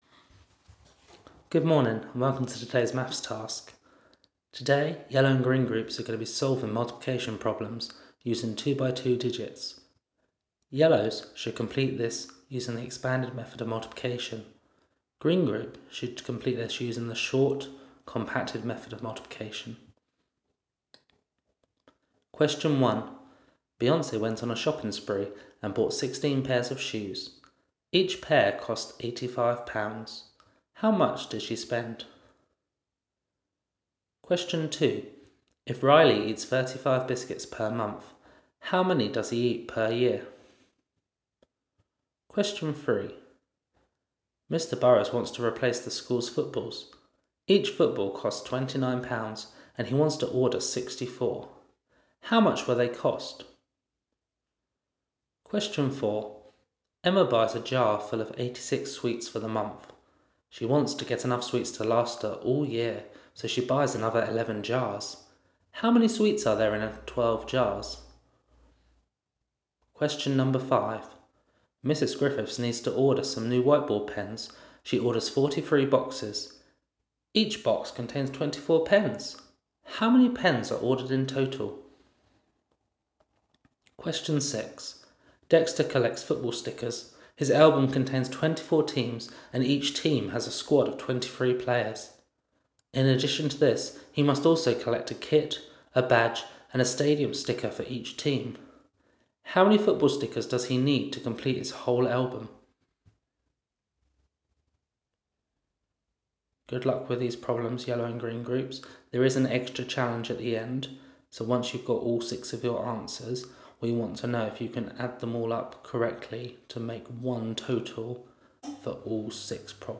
Here are some audio clips of the questions being read out loud.